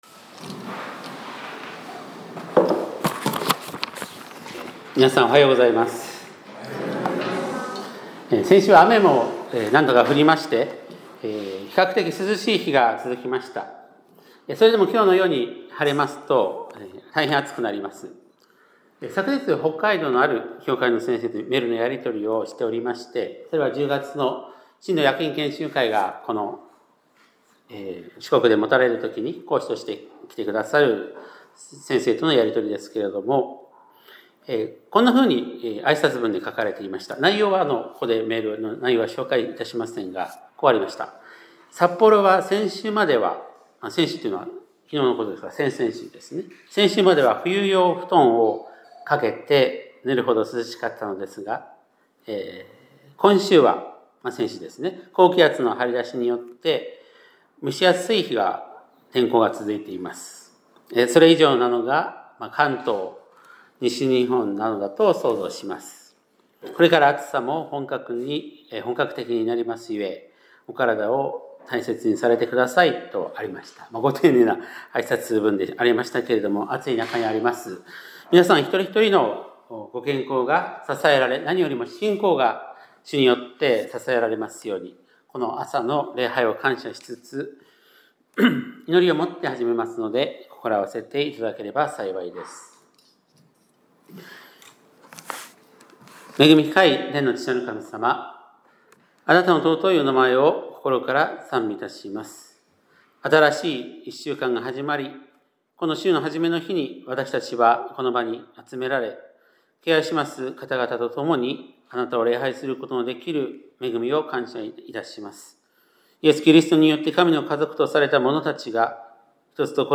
2025年7月20日（日）礼拝メッセージ - 香川県高松市のキリスト教会
2025年7月20日（日）礼拝メッセージ